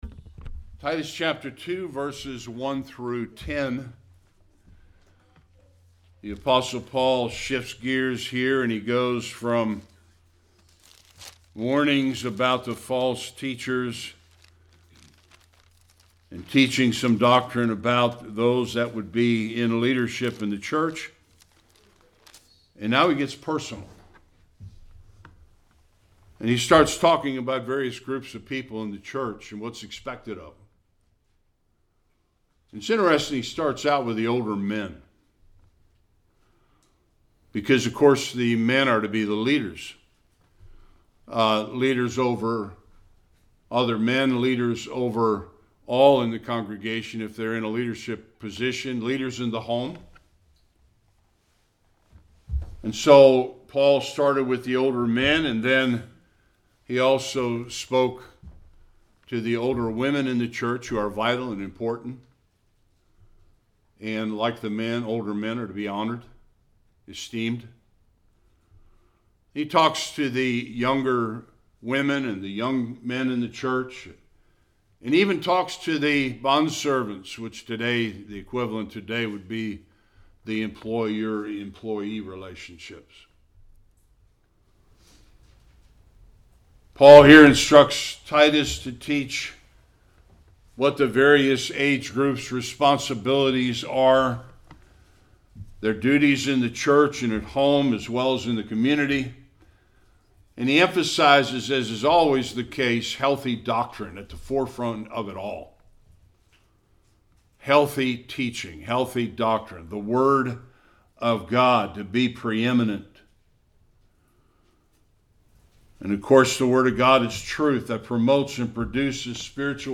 1 Service Type: Sunday Worship The importance of older men in the church.